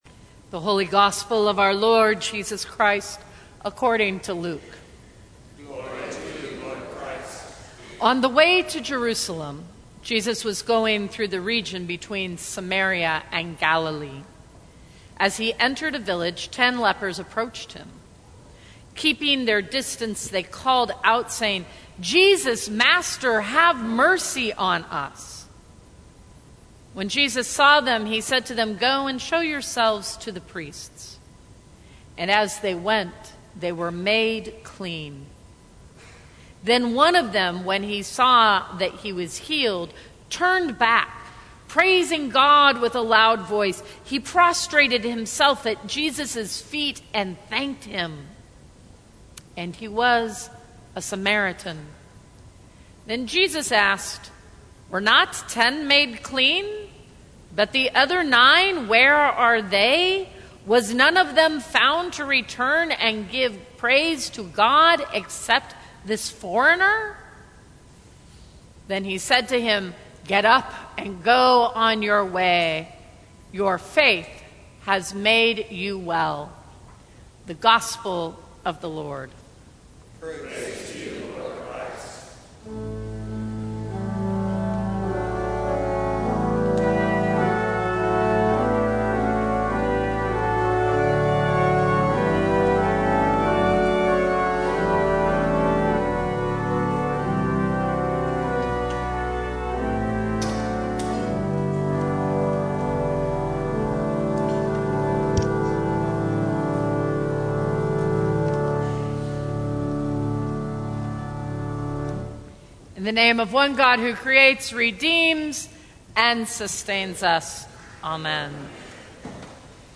Eighteenth Sunday after Pentecost, Gospel: Luke 17:11-19
Sermons from St. Cross Episcopal Church You Are God’s Beloved Oct 14 2019 | 00:17:47 1x 00:00 / 00:17:47 Subscribe Share Apple Podcasts Spotify Overcast RSS Feed Share Link Embed